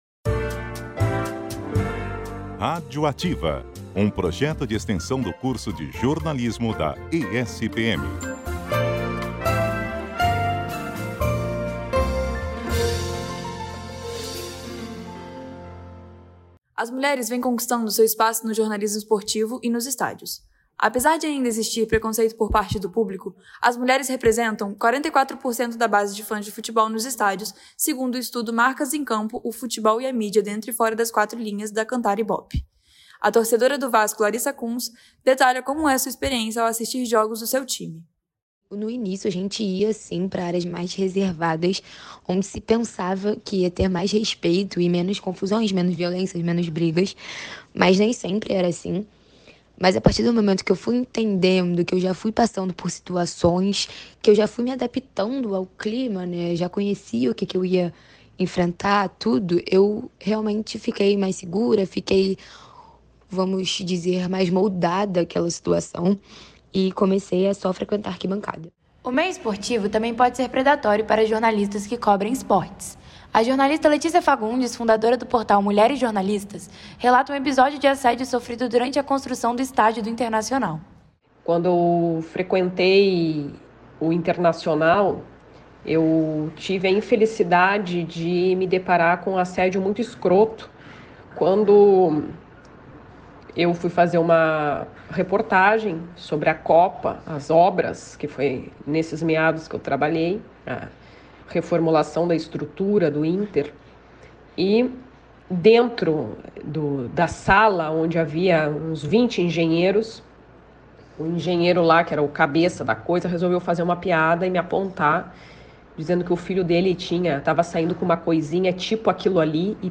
Trata-se de um projeto de extensão que reúne docentes do PPGECEI, da Graduação em Jornalismo da ESPM Rio e discentes na produção mensal de uma reportagem, com finalidade de divulgação científica.